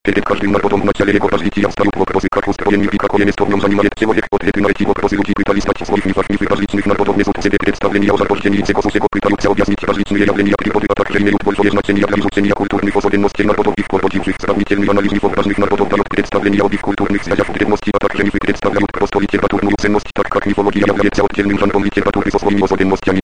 "Речевой синтезатор Капитан" - это MS SAPI5-совместимый синтезатор.
Основной голос обладает мужским голосом с хорошей разборчивостью речи, но низкой естественностью звучания.
Скачать демонстрационный аудиофайл основного голоса [136 kB]